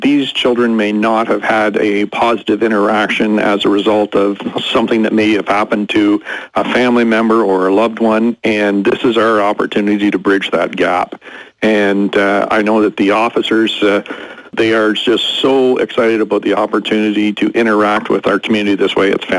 It was a special day at Quinte Mall Wednesday where some local police officers and youngsters came together for the 5th annual Cop Shop.
Belleville Police Chief Mike Callaghan says they really try to make it a meaningful day that the kids won’t forget.